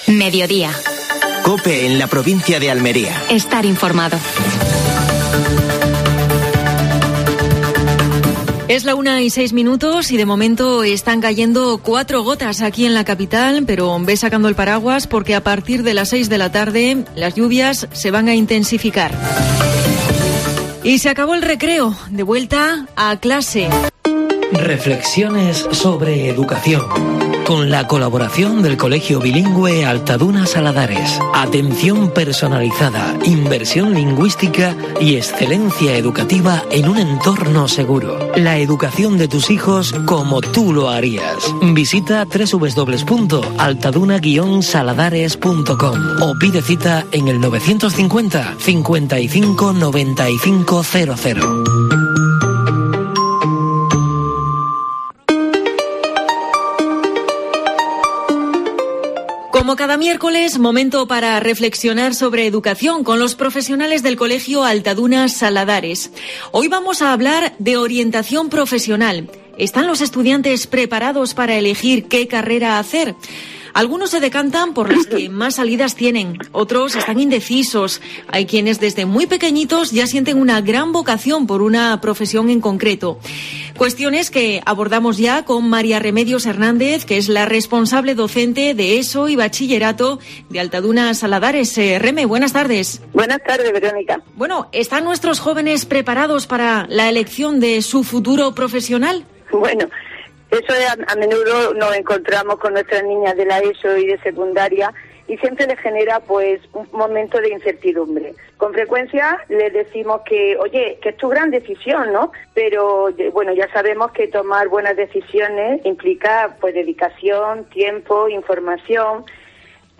Actualidad en Almería. Entrevista